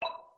added clicking sounds on button click
click.ogg